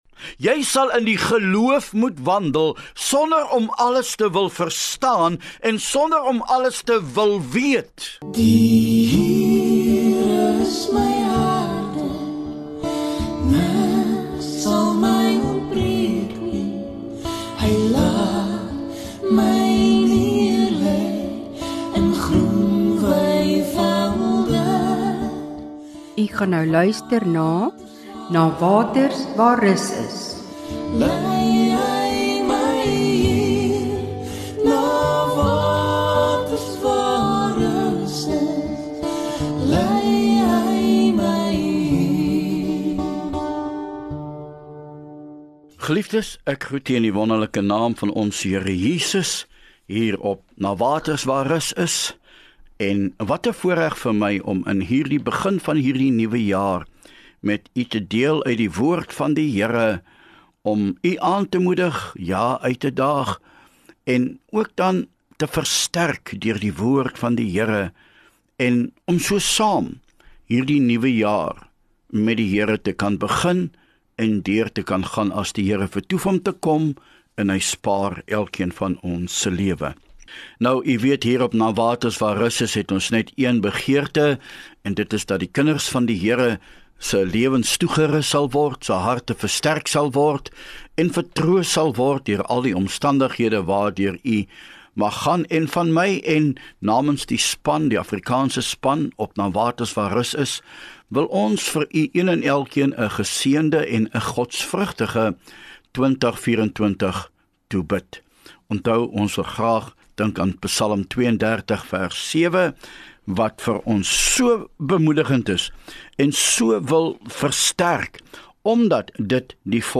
NUWE JAARS BOODSKAP